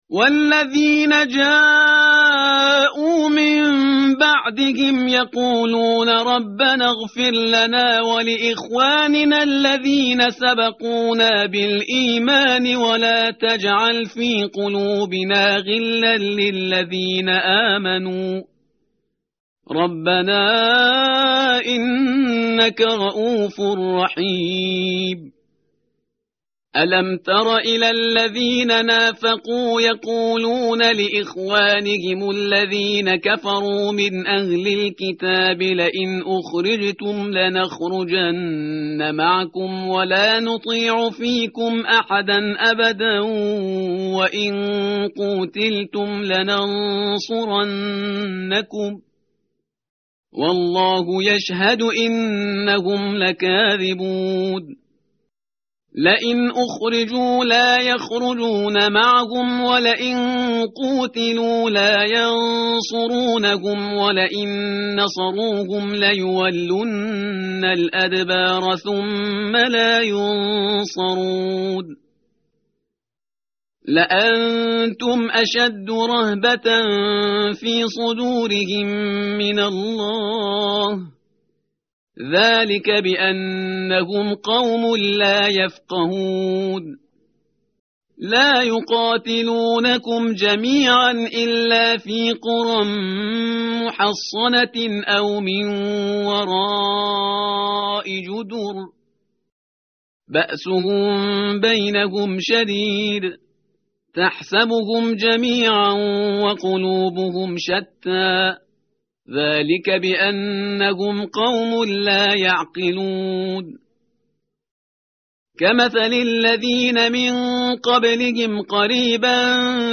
متن قرآن همراه باتلاوت قرآن و ترجمه
tartil_parhizgar_page_547.mp3